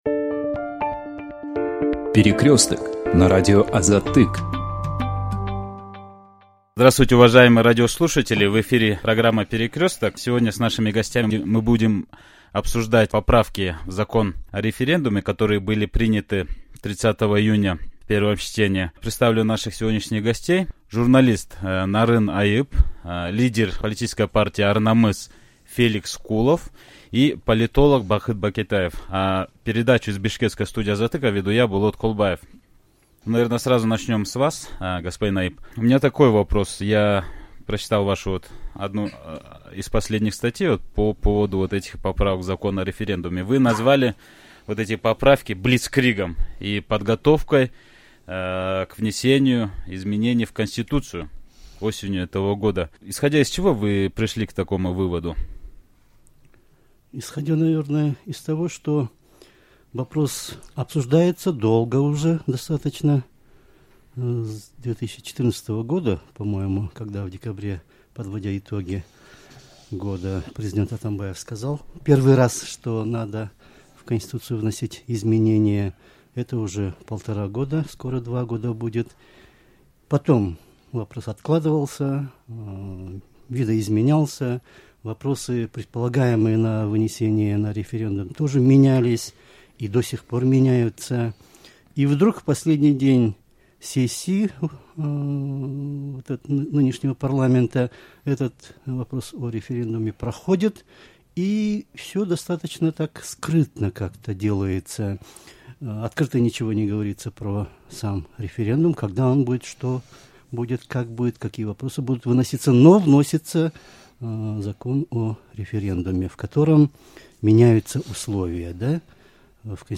В очередном выпуске радиопередачи «Перекресток» обсуждались поправки в закон о референдуме, принятые Жогорку Кенешем в первом чтении.